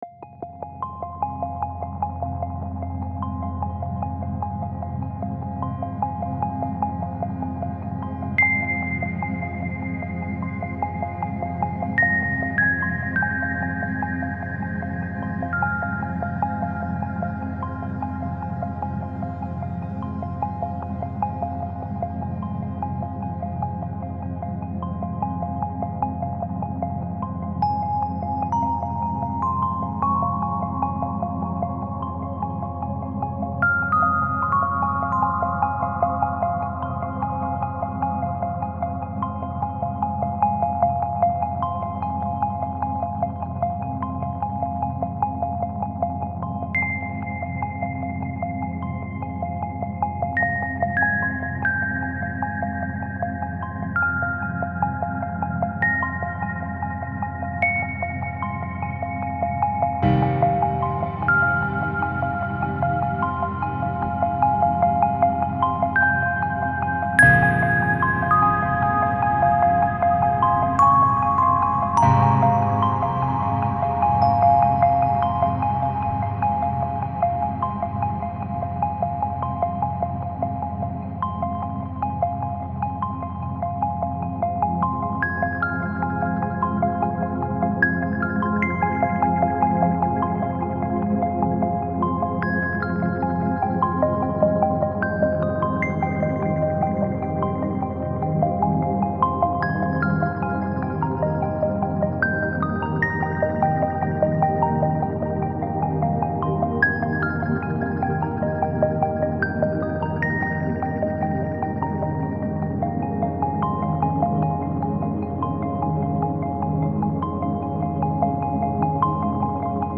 Wegen dem Piano: immer wenn ich es glatt mache, dann geht was vom Feeling verloren, es klingt dann weniger drückend in dem Moment.
Die Drums habe ich mal mit elektronischen Drums unterlegt, grooved jetzt mehr. Ein Synthbass erweitert noch mit 16tel den normalen Bass. Und dann noch eine sanfte Melodie im letzten Abschnitt mit den Drums.
V.a. wieder einmal ein großer dynamischer Umfang.